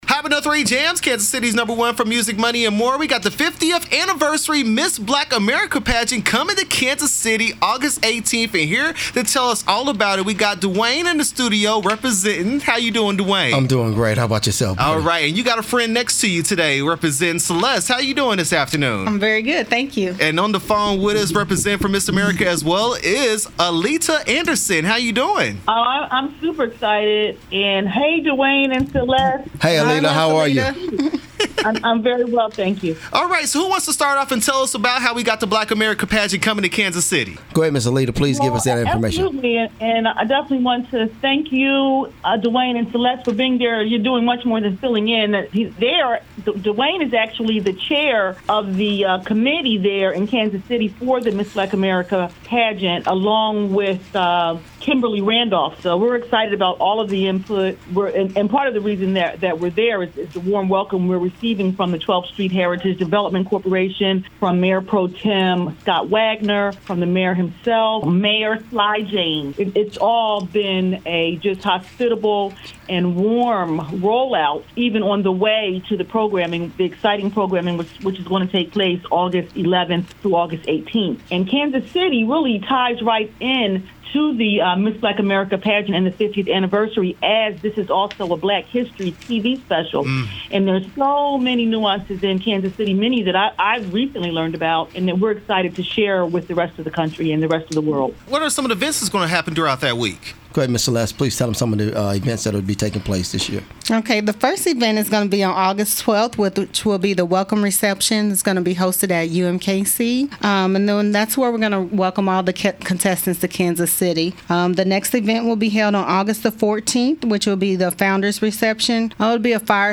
Miss Black America Pageant 2018 Interview